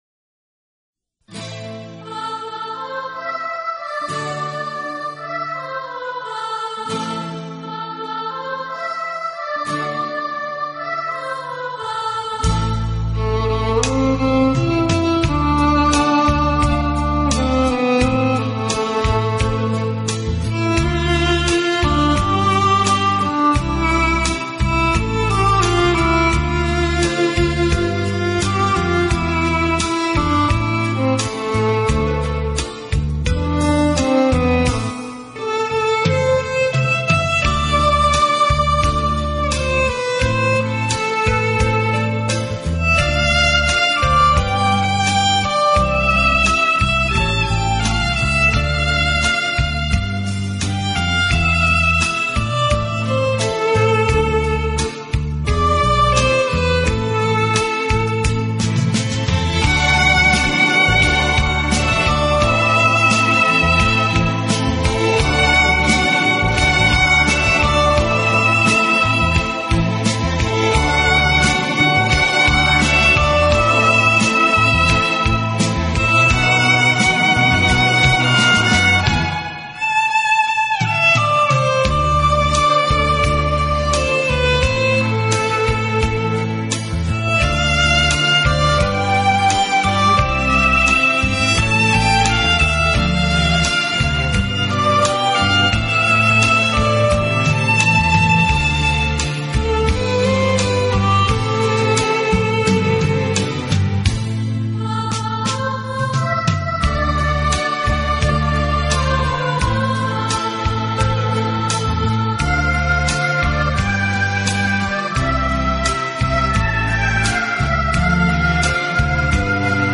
音乐类型：新世纪